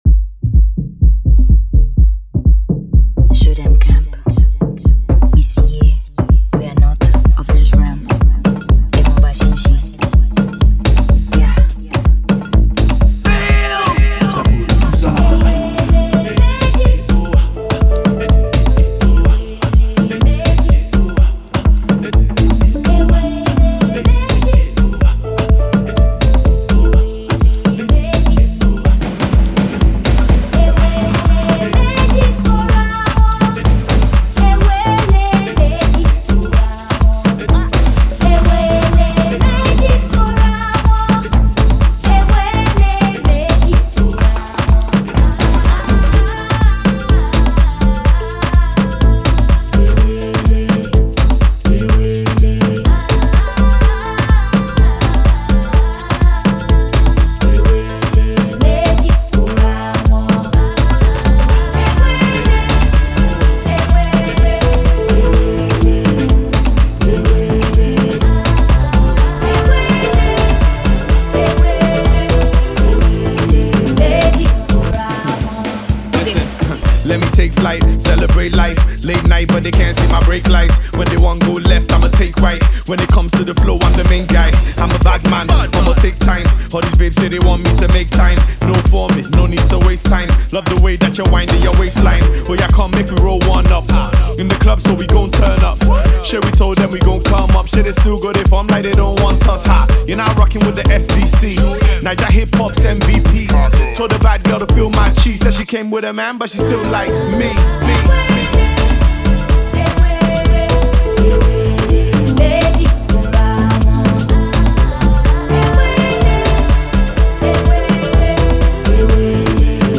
silky-voiced